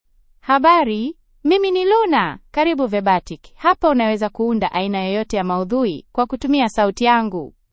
Luna — Female Swahili (Kenya) AI Voice | TTS, Voice Cloning & Video | Verbatik AI
FemaleSwahili (Kenya)
Voice sample
Listen to Luna's female Swahili voice.
Luna delivers clear pronunciation with authentic Kenya Swahili intonation, making your content sound professionally produced.